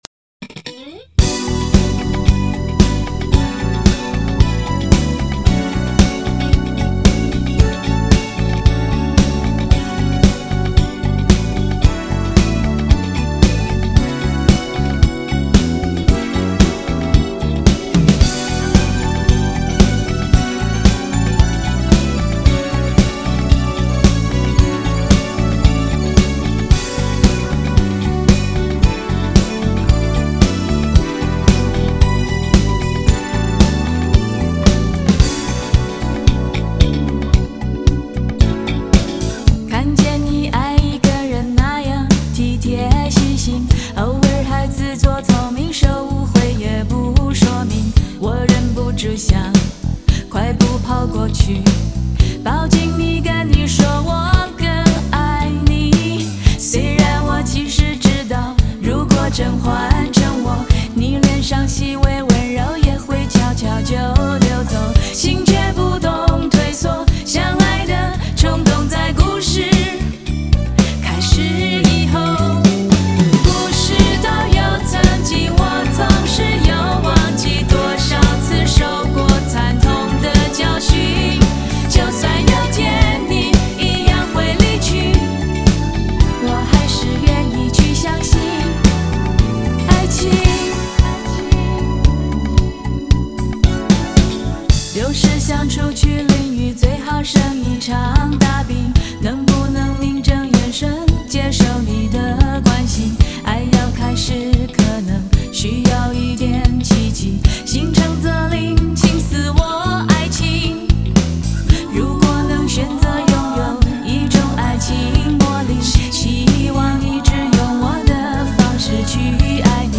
国风 收藏 下载